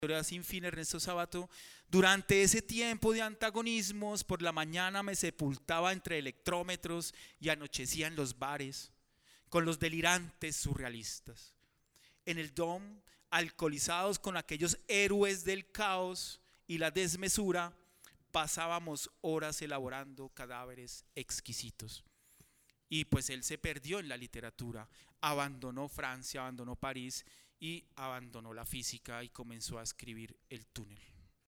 conversaron acerca de la relación entre poesía y ciencia en la conferencia Estado Sólido: Poesía y Ciencia, en el segundo día de actividades de la Feria Internacional del libro de Guadalajara 2022.